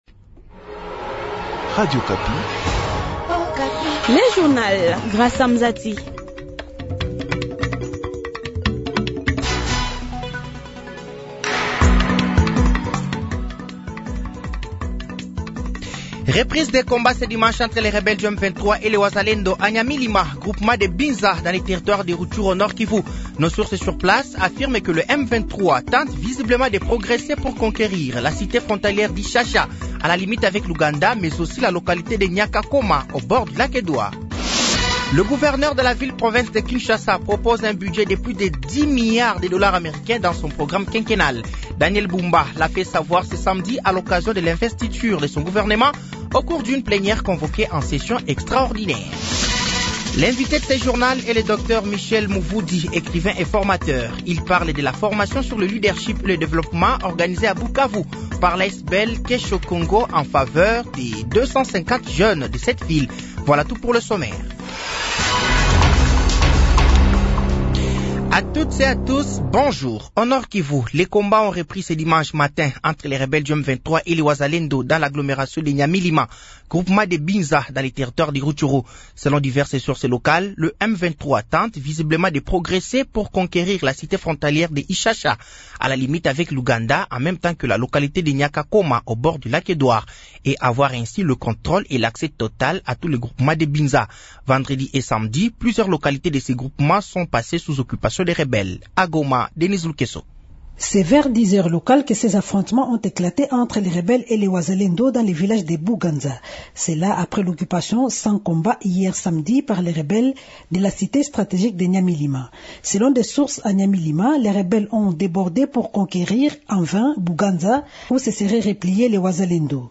Journal français de 15h de ce dimanche 04 août 2024